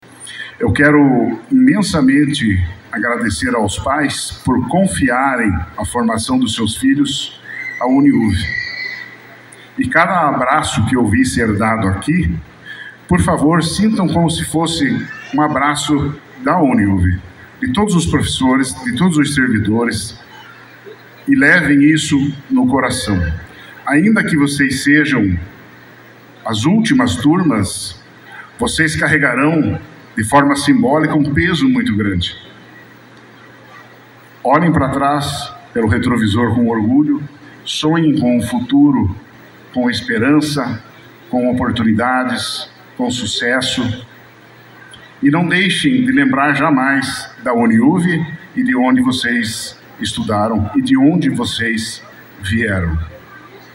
A última turma do Centro Universitário de União da Vitória (UNIUV), agora incorporado à Universidade Estadual do Paraná (Unespar), celebrou a colação de grau e o tradicional baile de formatura nas dependências do Wooden Hall.